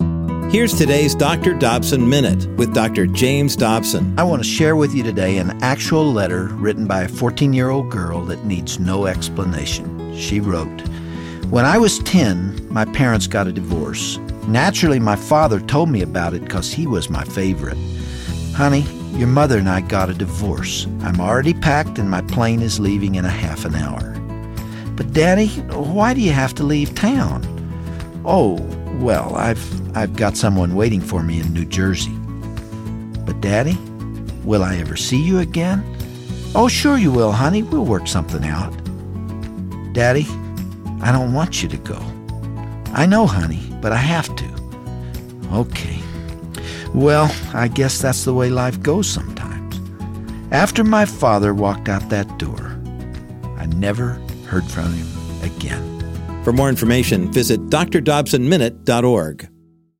Dr. Dobson reads a letter he received from a 10-year-old girl describing how her father announced he was divorcing her mother and leaving town, and never heard from him again.